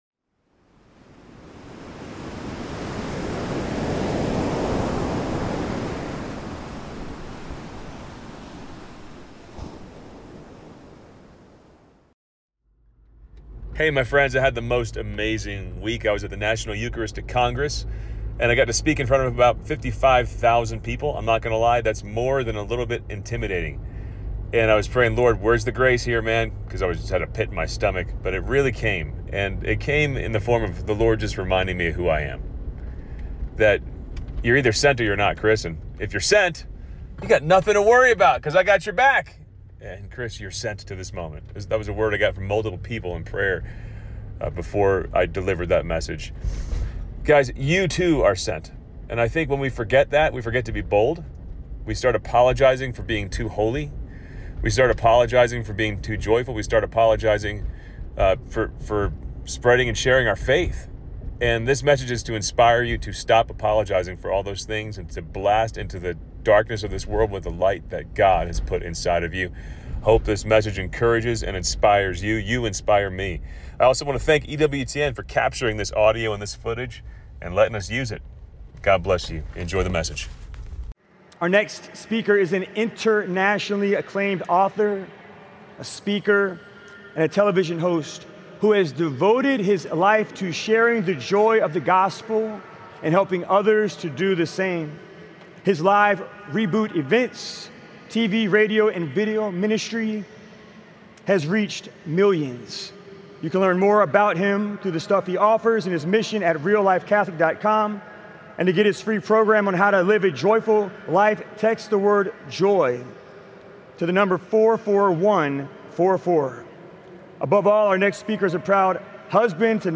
Full Speech at the 2024 National Eucharistic Congress